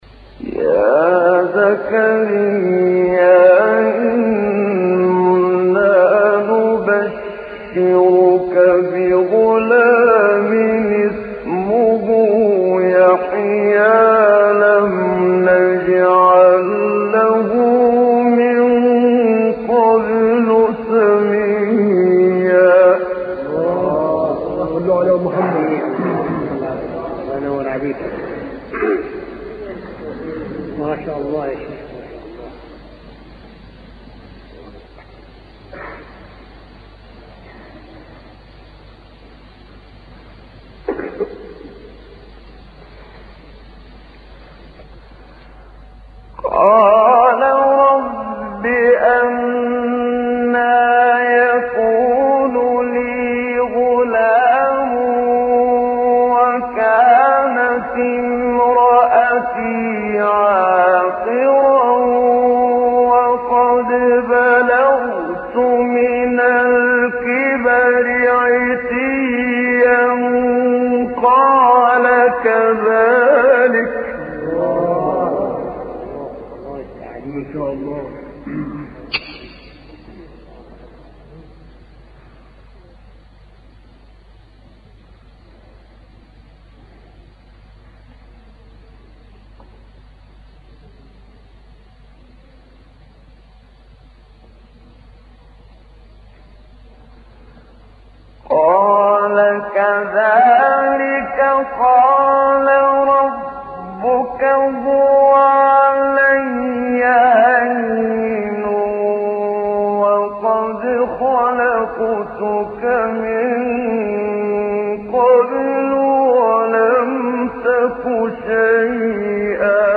آیه 7-22 سوره مریم استاد محمد صدیق منشاوی | نغمات قرآن | دانلود تلاوت قرآن